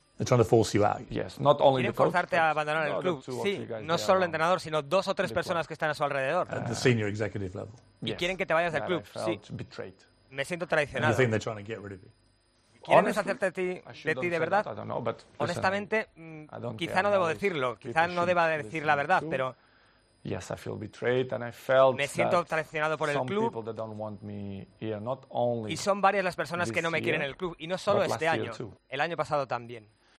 Entrevista en TalkTV